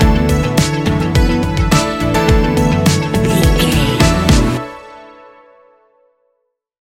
Ionian/Major
ambient
electronic
new age
chill out
downtempo
synth
pads